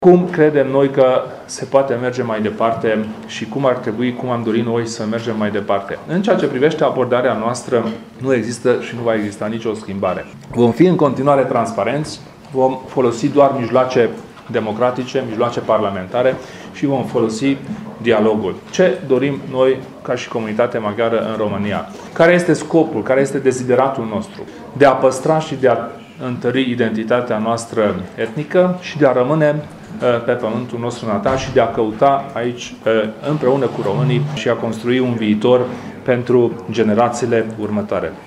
Președintele UDMR, Kelemen Hunor, a salutat reunirea la aceeași masă a atâtor factori care se pot implica în soarta minorităților.